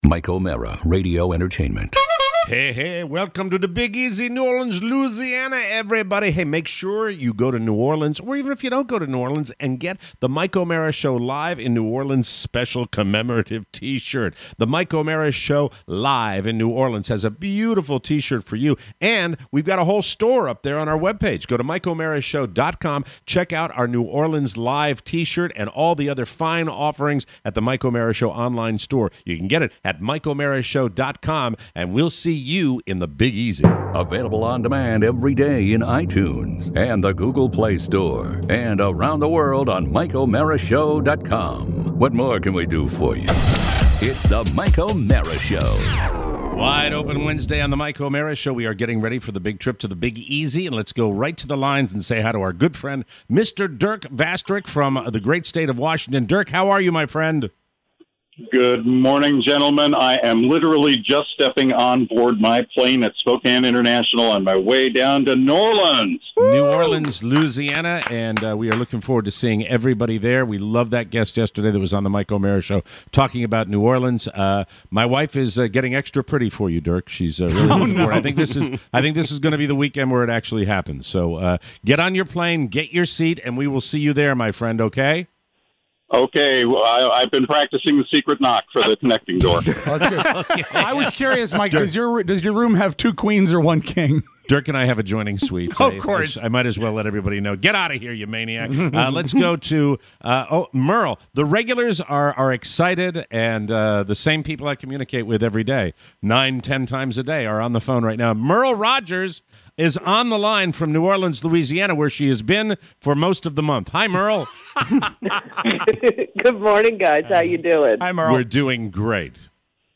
Calls calls calls! Plus… love for New York… Apple’s bra obsession and hockey helmets.